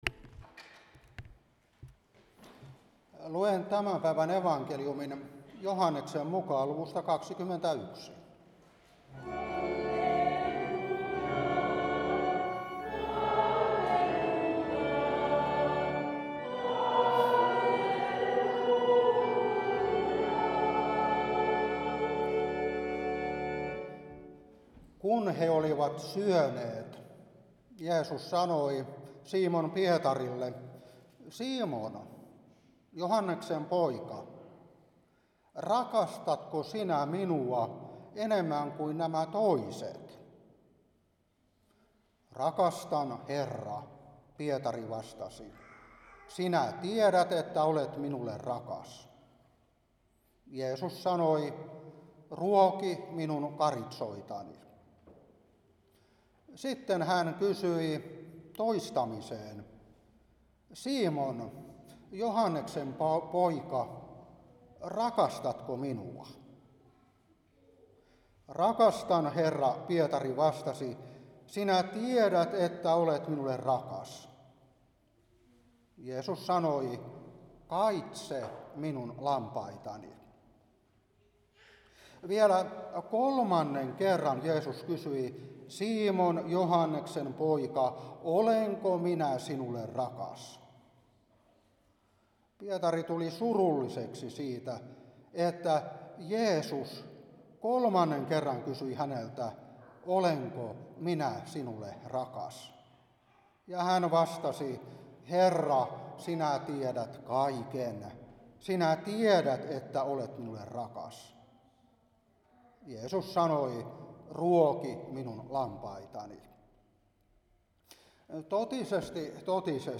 Saarna 2026-4 Joh.21:15-19